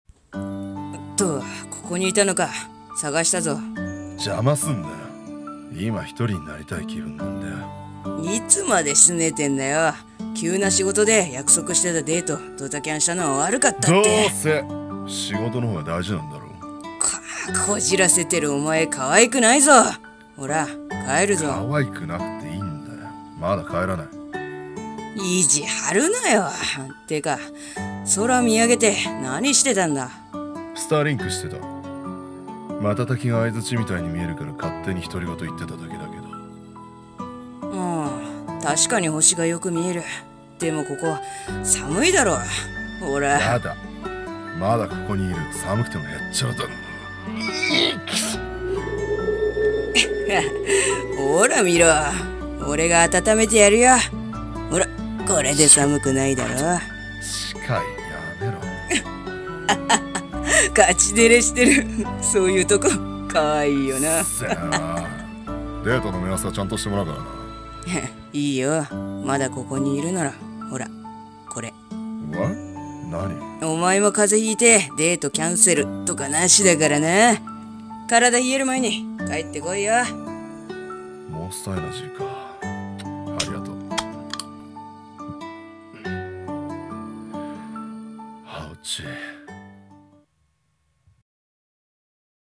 冬という名の温もり。【声劇台本】